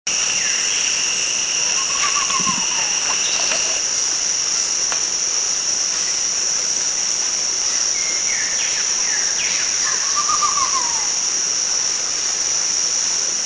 Philippine Trogon
Harpactes ardens
PhilippineTrogon.mp3